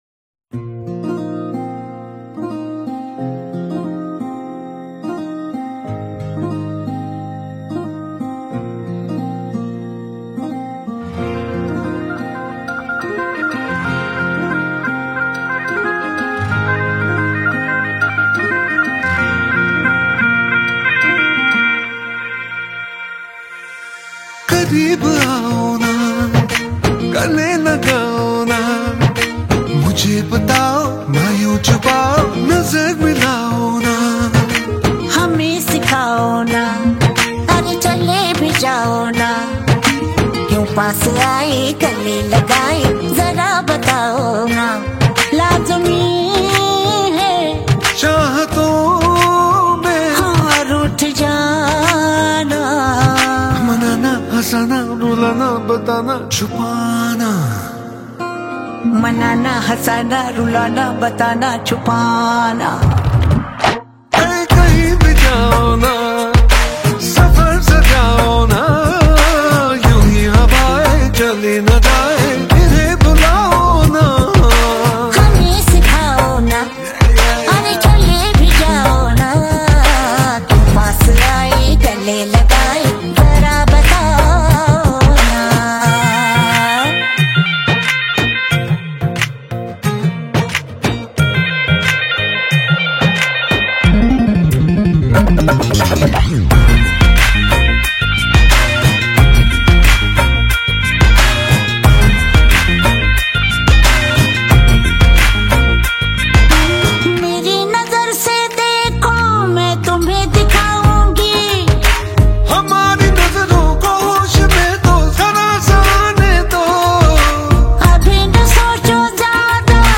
creating a heart touching melody.